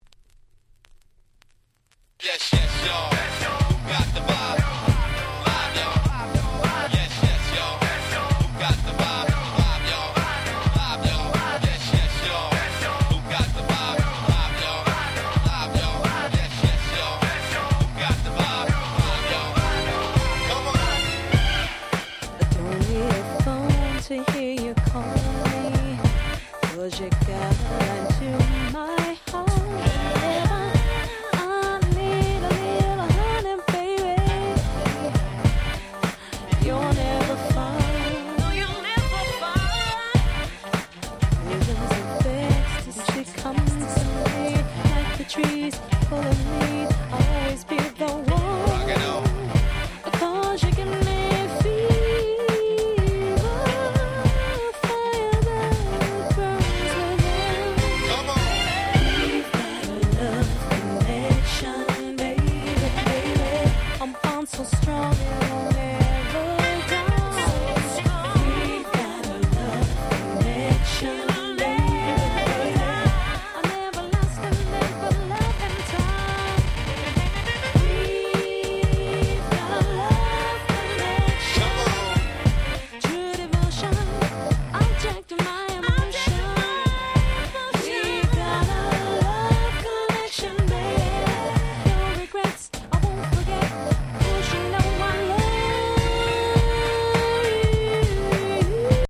95' Super Nice R&B !!